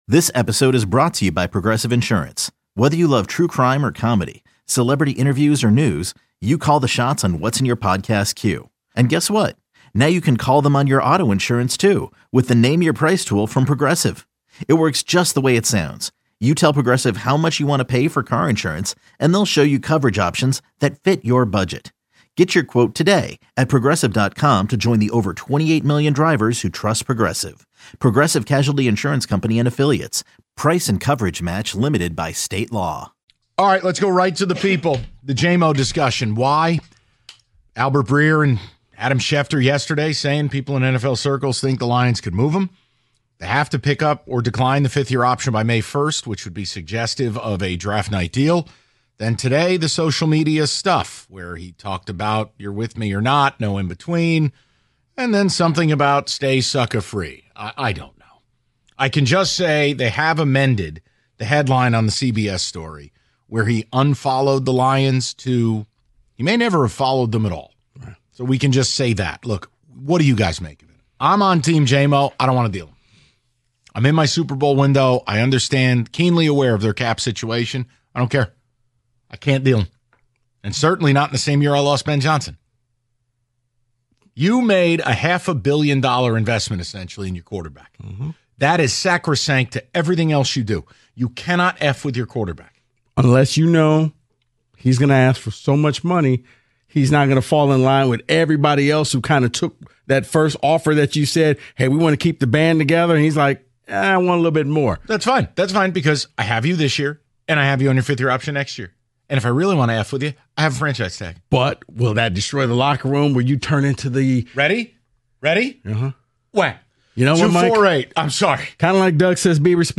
taking a couple more of your calls on the Jamo situation.